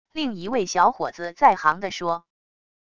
另一位小伙子在行地说wav音频